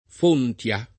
[ f 1 nt L a ]